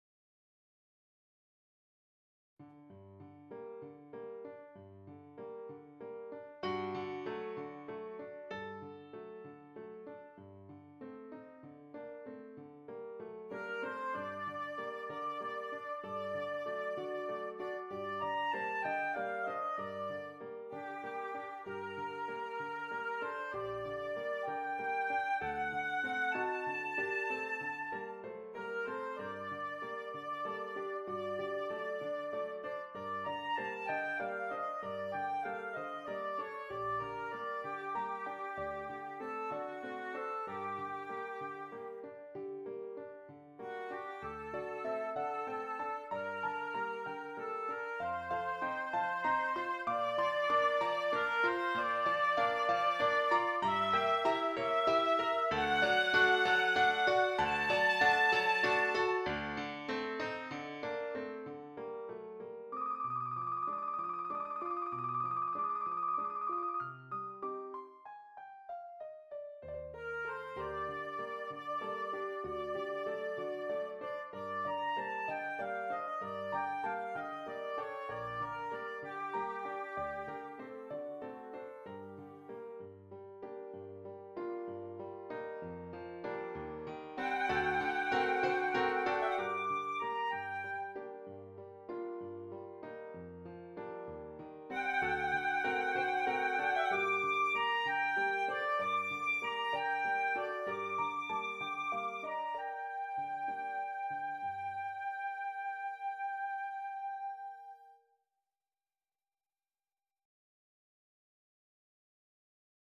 Oboe and Keyboard